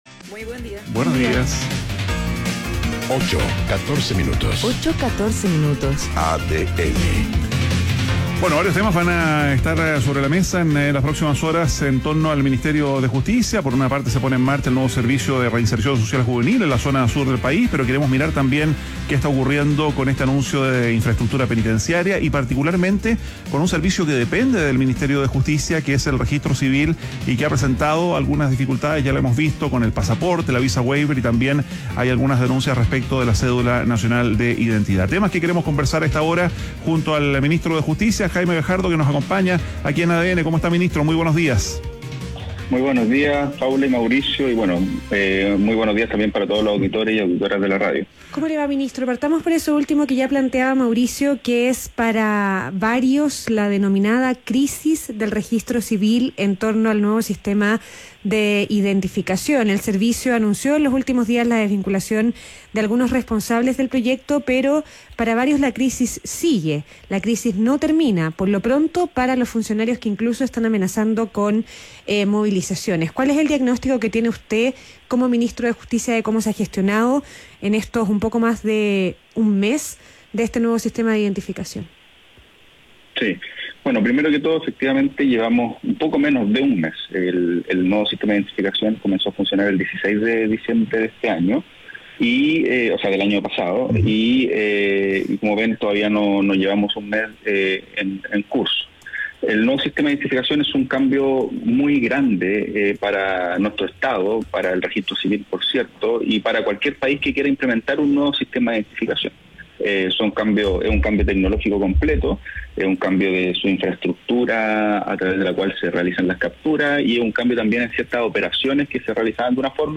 Ministro de Justicia, Jaime Gajardo, conversa con ADN Hoy